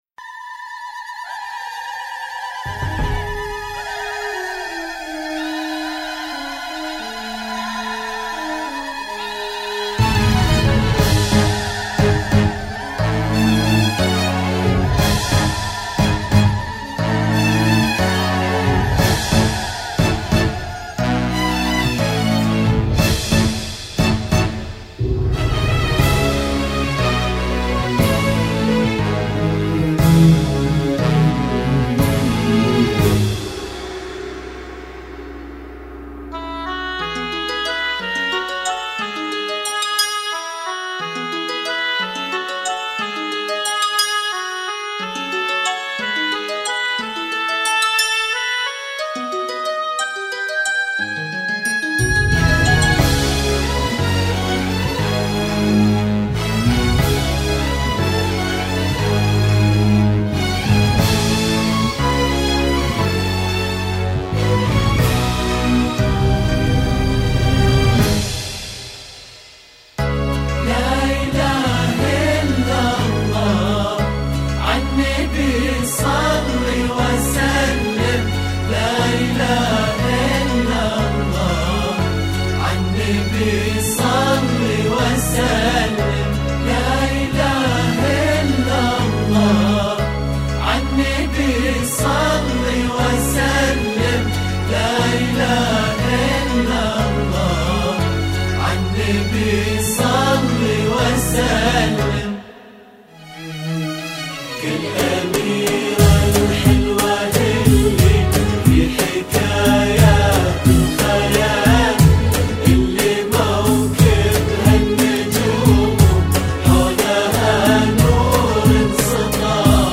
بذكاء الاصطناعي
زفات السعودية
موسيقي وبدون موسيقي